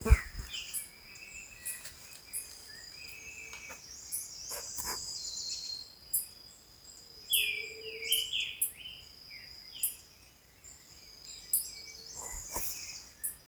Pioró (Thlypopsis pyrrhocoma)
Nombre en inglés: Chestnut-headed Tanager
Localidad o área protegida: Reserva Privada San Sebastián de la Selva
Condición: Silvestre
Certeza: Fotografiada, Vocalización Grabada